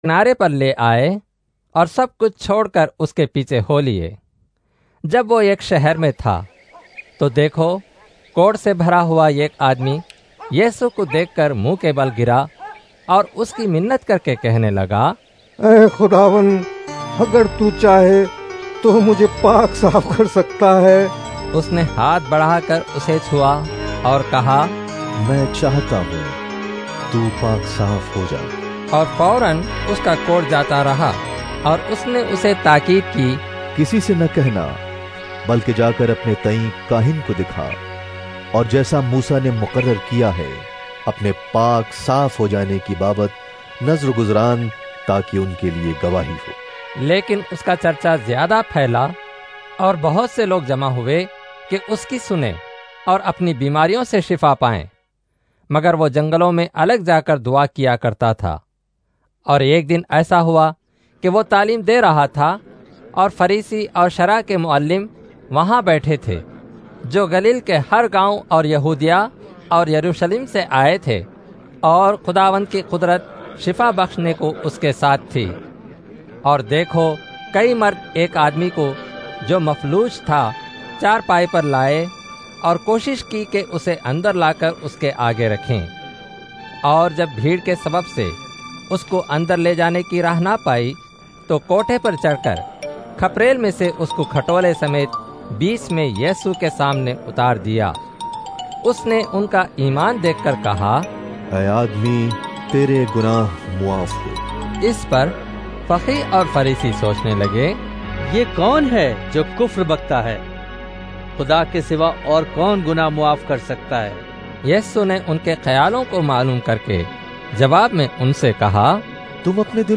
Urdu Audio Drama Bible New Testament - United Evangelical Christian Fellowship(UECF), New Jersey - Popular Christian Website Telugu Hindi Tamil Malayalam Indian Christian audio Songs and daily bible devotions